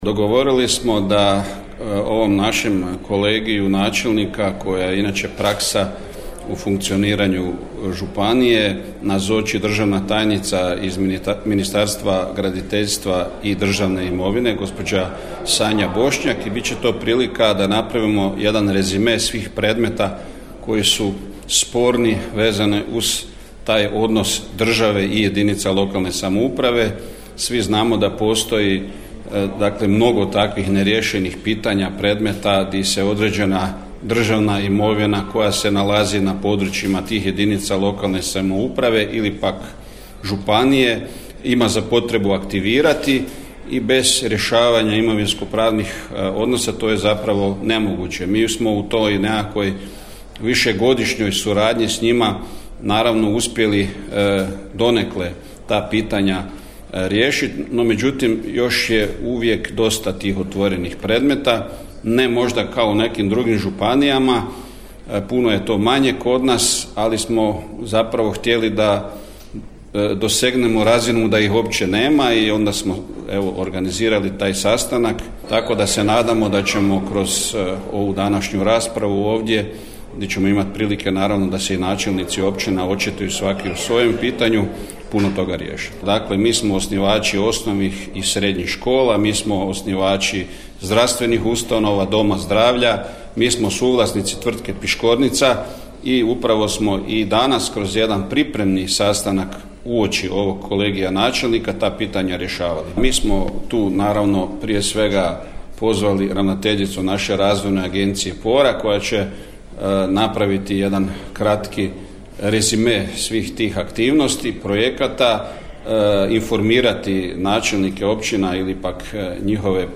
U prostoru zgrade Županijske uprave održan je kolegij načelnika i gradonačelnika s županom Darkom Korenom koji je i sam naglasio koje su glavne teme sastanka: